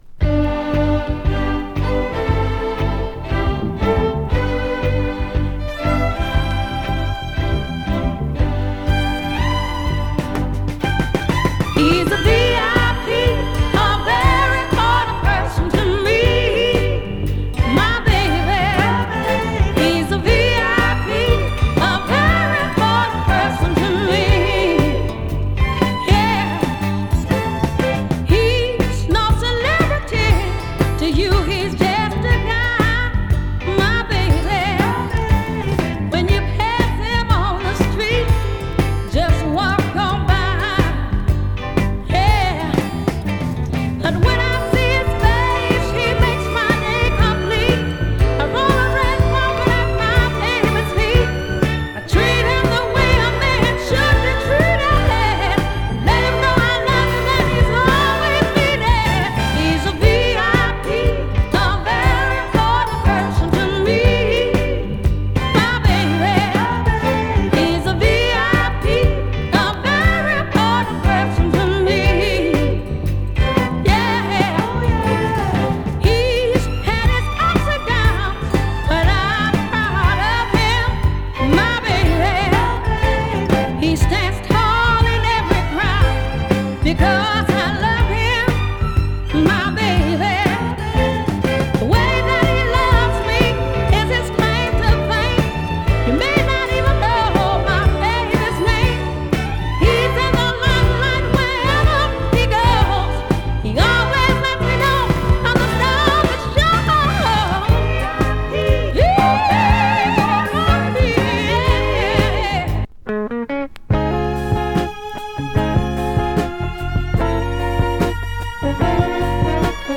7inch
Soul　女性ソウルシンガー
Side A中盤で小キズにより小さくノイズを
感じますが他は両面共再生概ね良好です。
試聴曲＝Side B→Side A記載ノイズ部分収録(1:53～)
※実物の試聴音源を再生状態の目安にお役立てください。